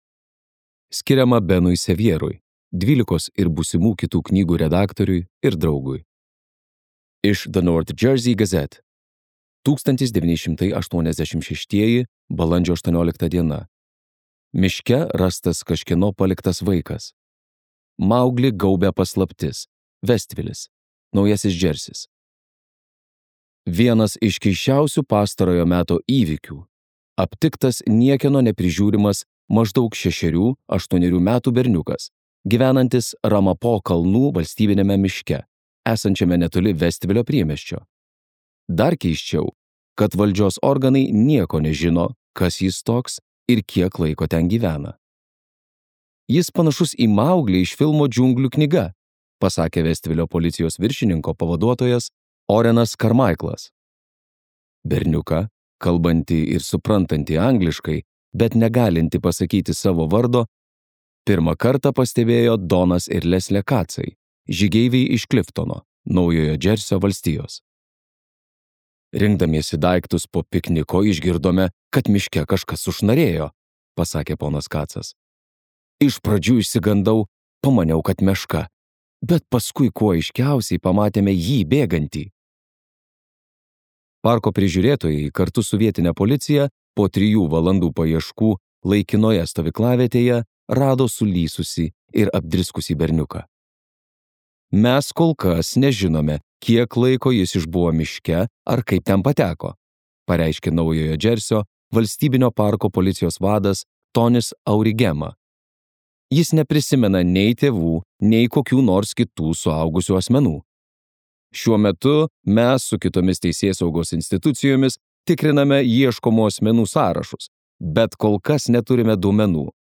Harlan Coben audioknyga „Vaikis iš miško“. Tai pasakojimas apie paslaptingomis aplinkybėmis dingusių paauglių paiešką, kurios imasi atsiskyrėlis ir nepritapėlis Vaildas.